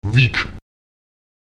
w [w] entspricht einem w-Laut wie in Wunder.
Lautsprecher wik [wik] das Gras